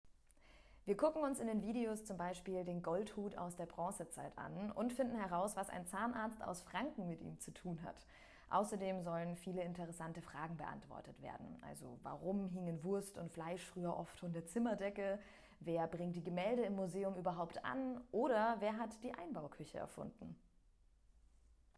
O-Töne